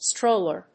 音節stróll・er 発音記号・読み方
/ˈstrolɝ(米国英語), ˈstrəʊlɜ:(英国英語)/